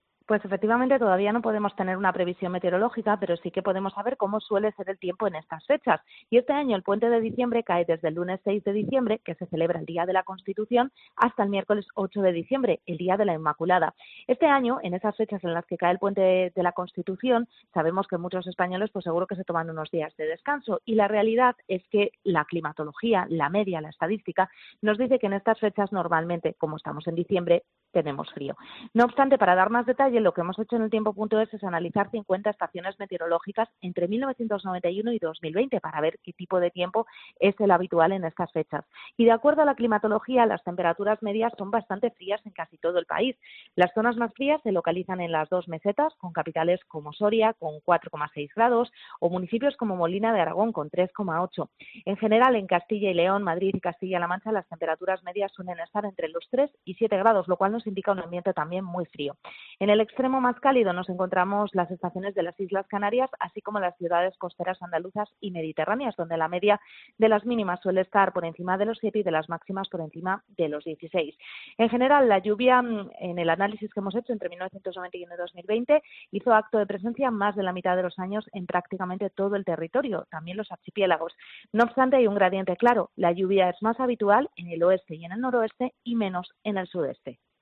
La respuesta rápida es clara: estamos en diciembre y eso implica frío, pero la meteoróloga da más detalles en el programa.